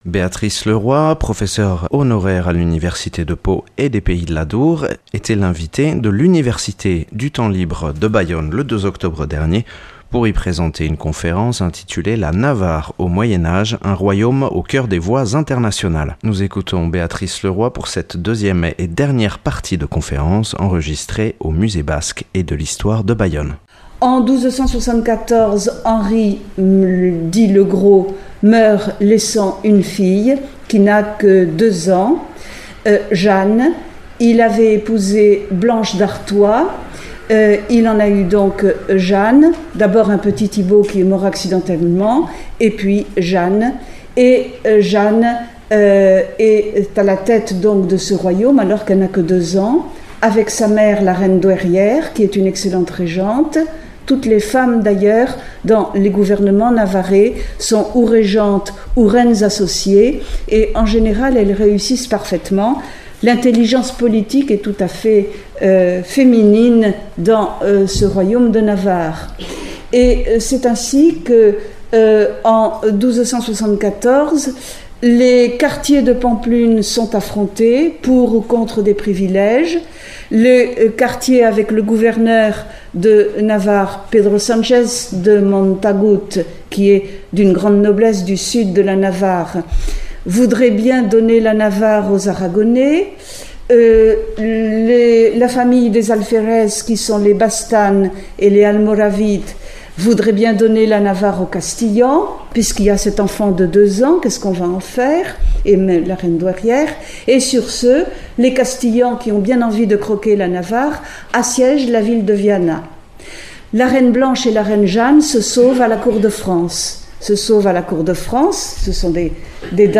Enregistré le 02/10/2015 dans le cadre de l’université du temps libre de Bayonne